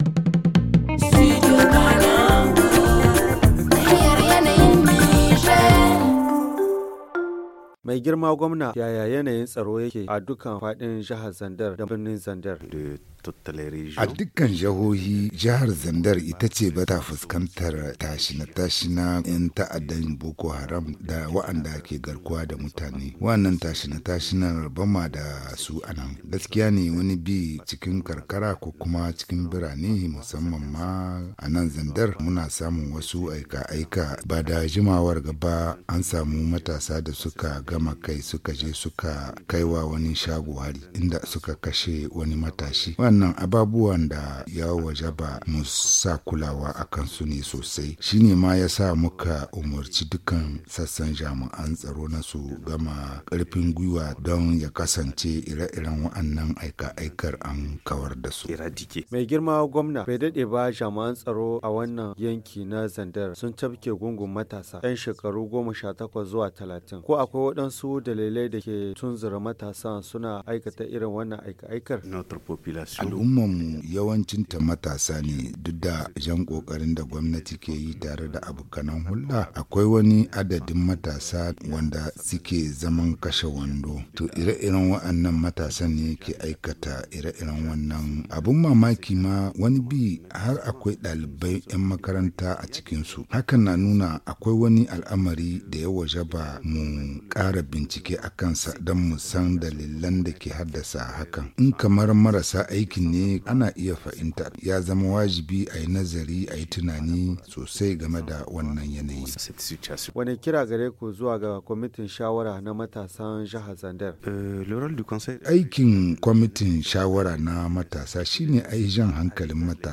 La situation sécuritaire dans la région de Zinder expliquée par le gouverneur - Studio Kalangou - Au rythme du Niger